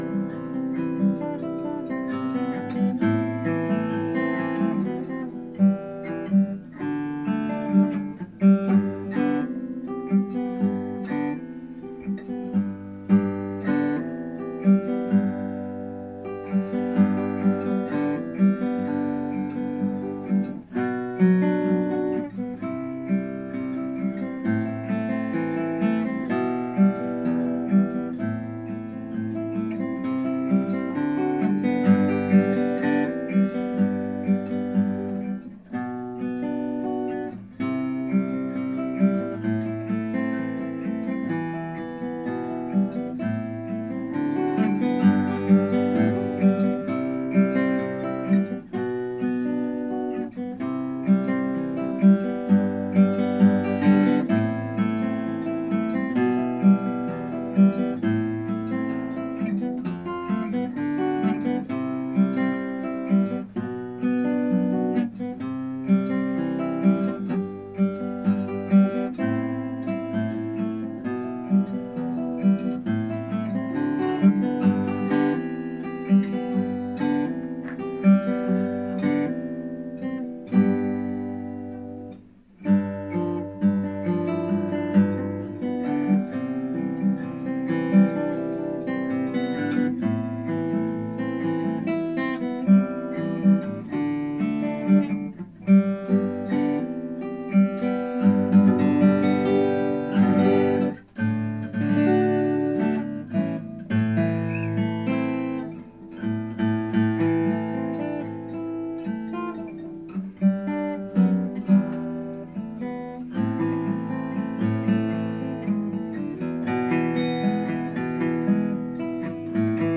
Note: This is simply a sample phone recording of "Trigger" and as such does not accurately represent the full range and beautiful sound of the guitar!